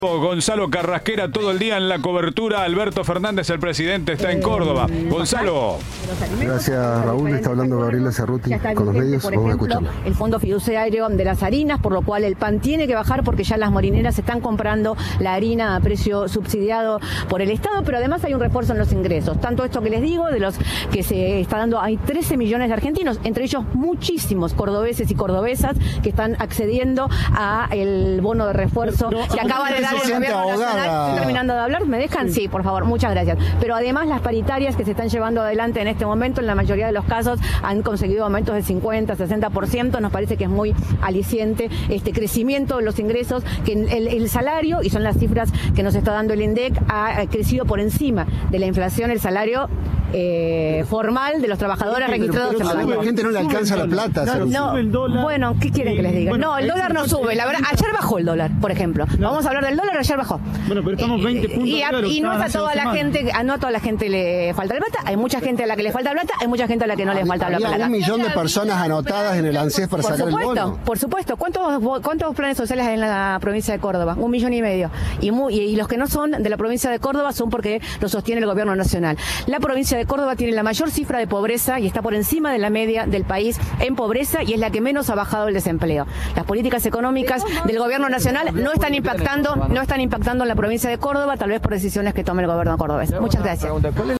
En rueda de prensa, Cerruti se refirió a aspectos de la economía como los salarios, el dólar y la pobreza.